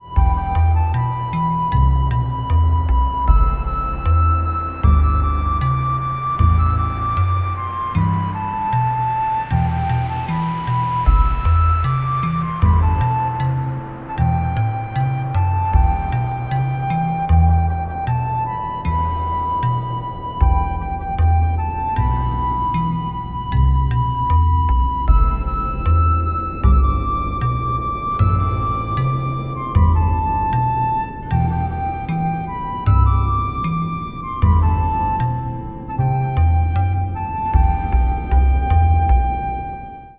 9:11 77bpm
12-string LucyTuned guitar